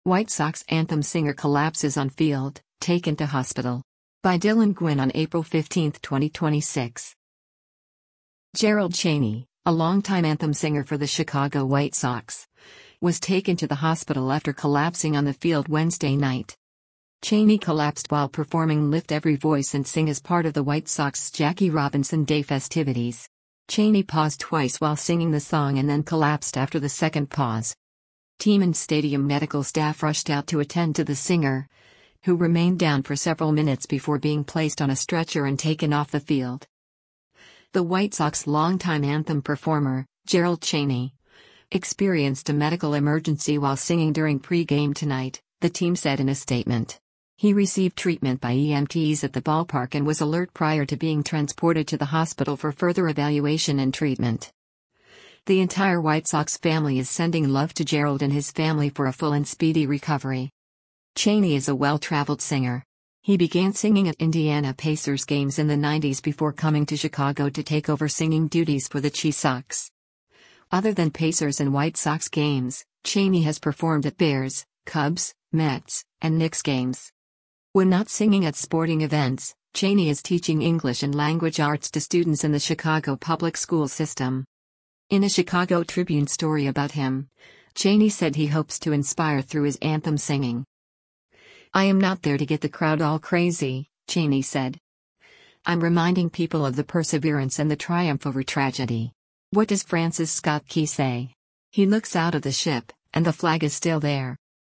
Jackie Robinson Day festivities
paused twice while singing the song and then collapsed after the second pause.